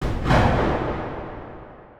Impact 27.wav